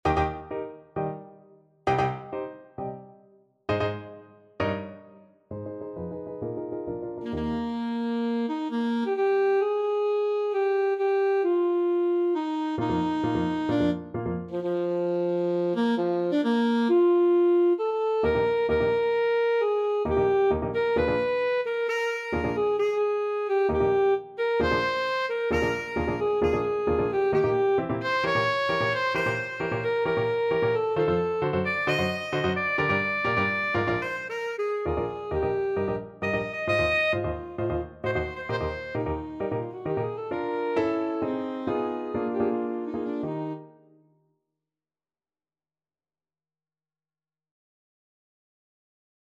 Alto Saxophone version
Classical (View more Classical Saxophone Music)